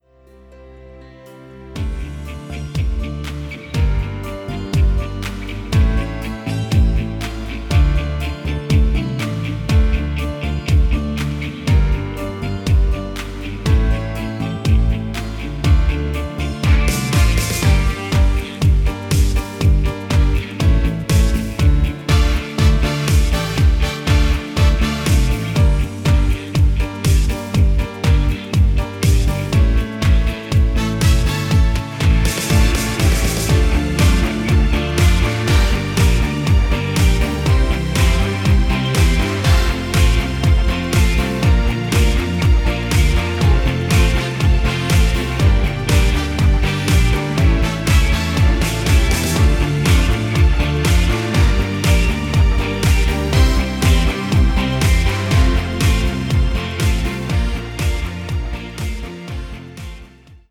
Ein Schlager und Party-Kracher